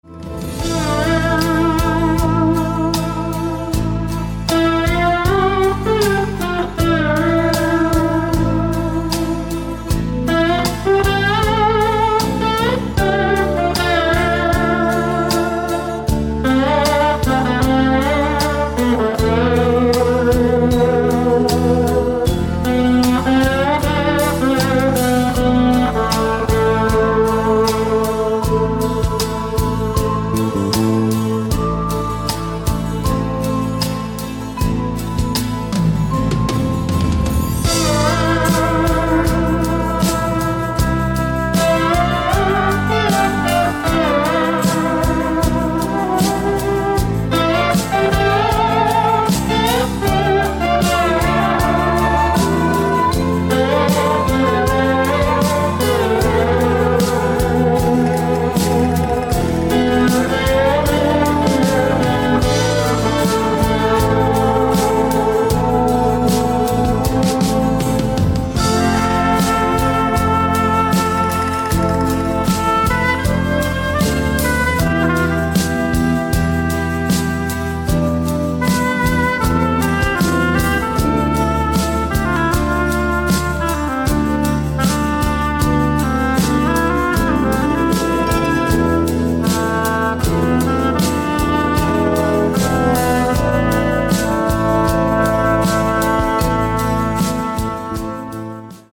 Романтические рингтоны